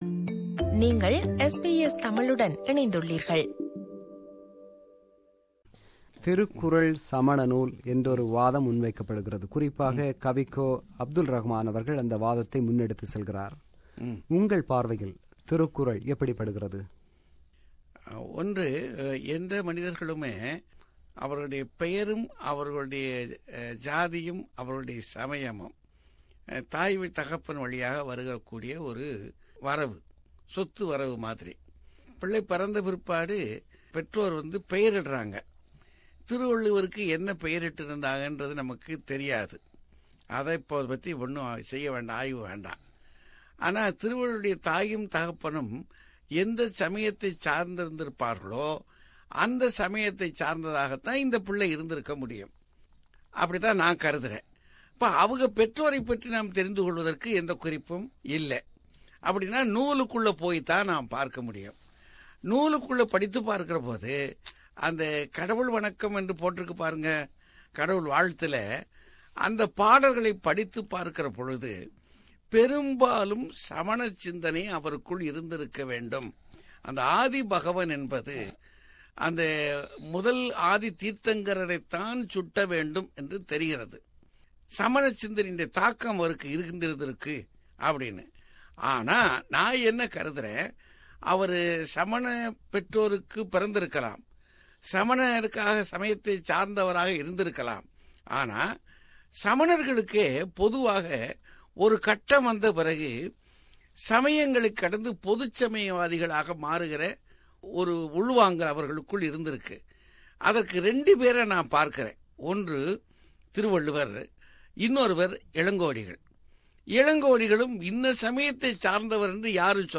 தமிழ் உலகு நன்கறிந்த பேச்சாளர் சாலமன் பாப்பையா அவர்கள். தனக்கு பிடித்த இலக்கியம் எது என்று விளக்குகிறார் ஆஸ்திரேலியா வருகை தந்திருந்த சாலமன் பாப்பையாஅவர்கள்.